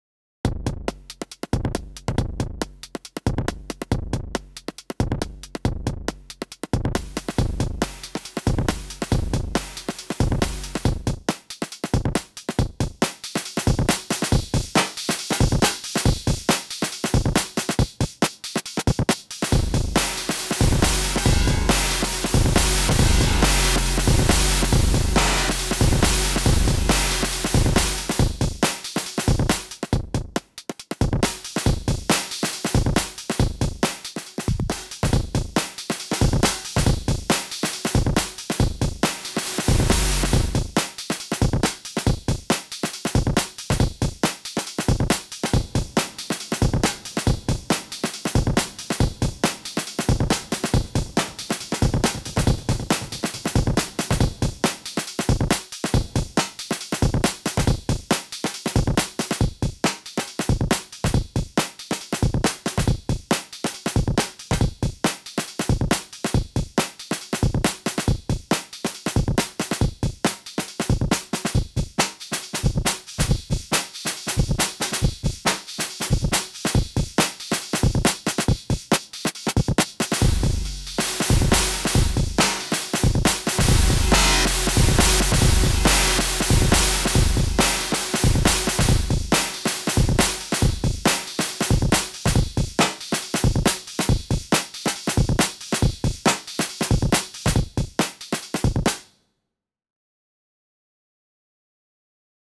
Breakbeat FMadness